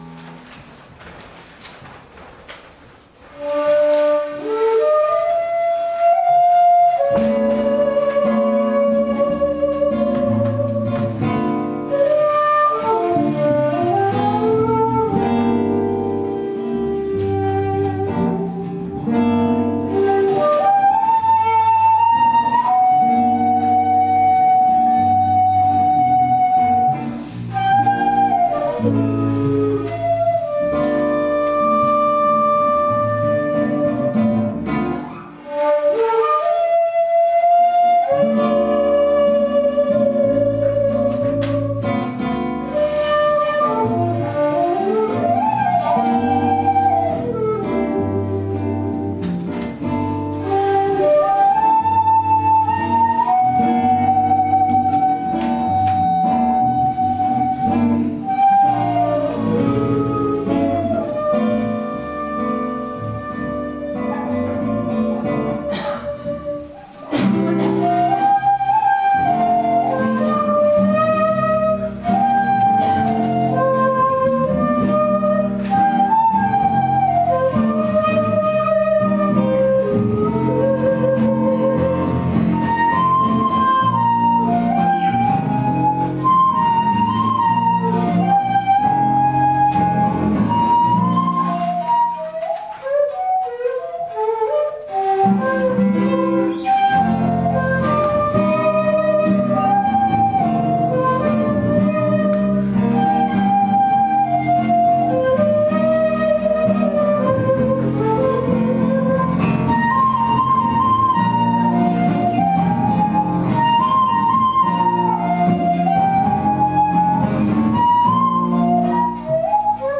まずは「Harlem Nocturne」（地無し一尺八寸管）、あのテナーサックス奏者のサム・テイラーが演奏ジャズ曲。
この泉佐野ふるさと町屋館の会場は天井が高く、ほどよく音が響きます。
<<参考音源>>　今回の新緑コンサートより↓